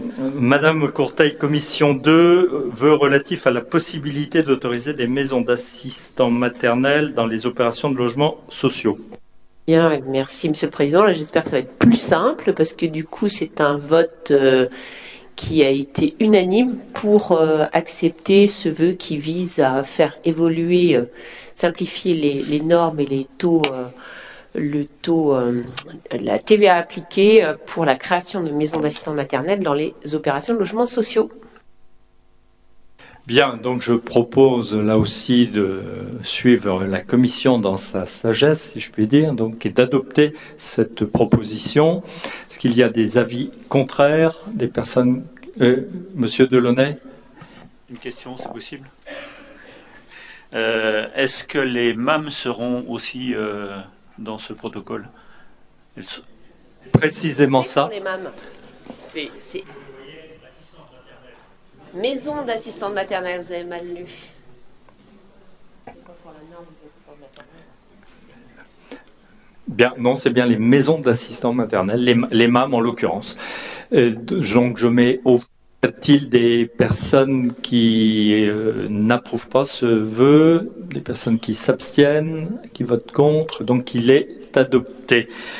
Famille, Enfance, Prévention Nature Assemblée départementale